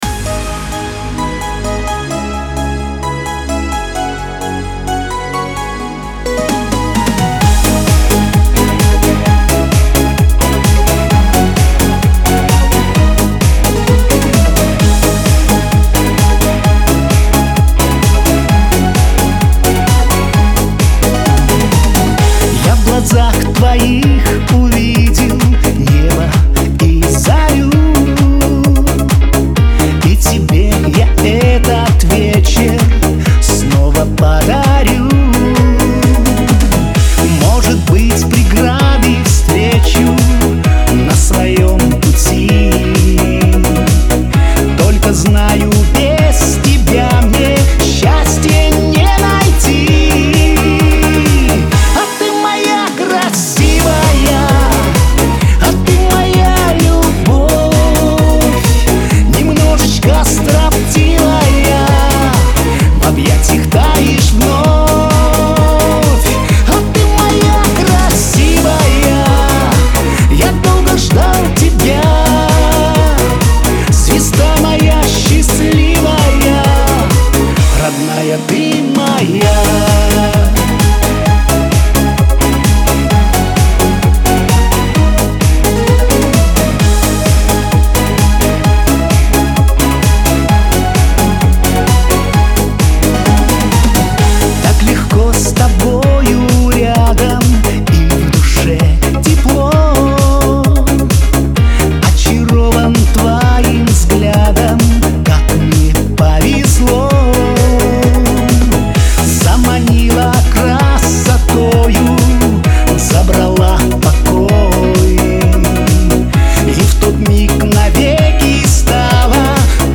pop
эстрада